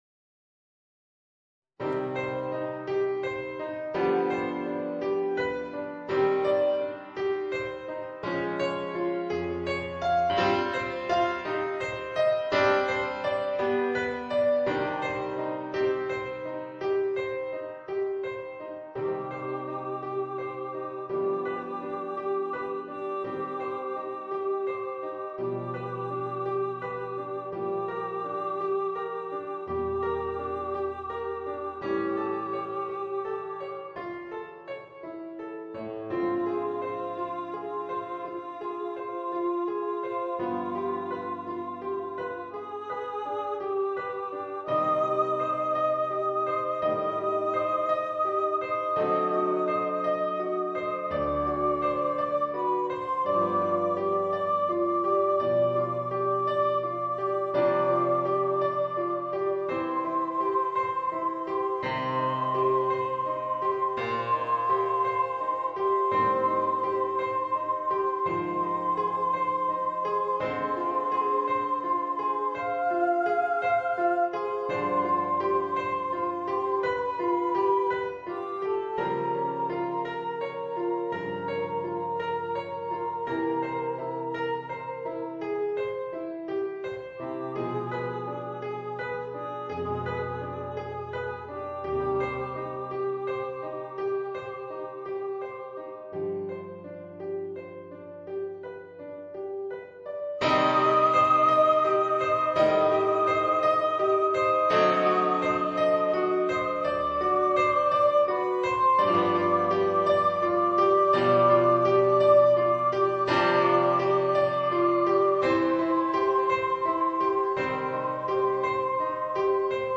Voicing: Voice and Piano